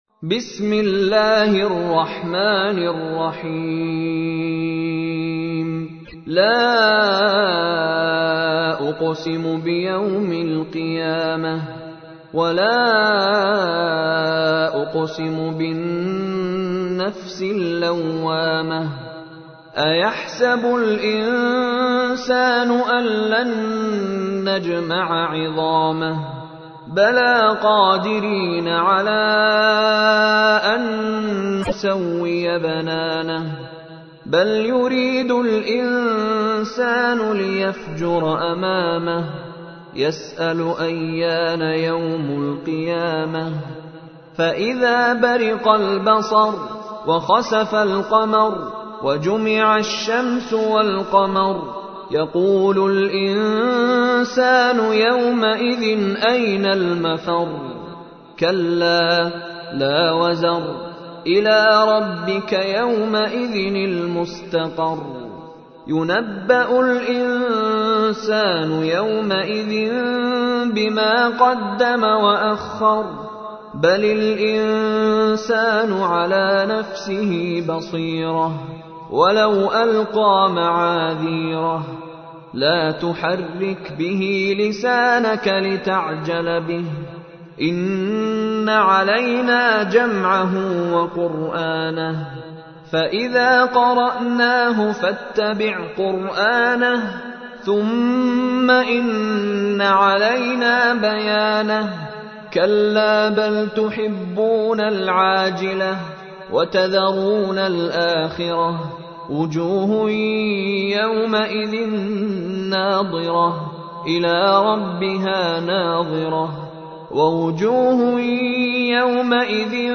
تحميل : 75. سورة القيامة / القارئ مشاري راشد العفاسي / القرآن الكريم / موقع يا حسين